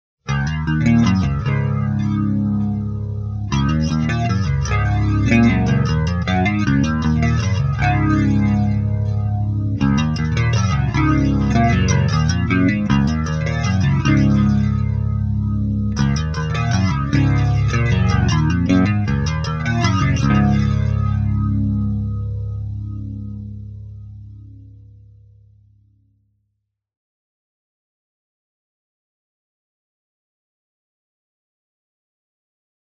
This is:  the world's first Barber Pole Through-Zero Flanger.
It can even go through the zero-point (that amazing over the top wooshing sound you hear on so many records), continually.
As if that weren't enough, you get both positive (swishy, phaser-y sounds) and negative (tubular, hollow, sucking-into-itself sounds) regeneration control.
From draggingly slow to space-ray-gun fast.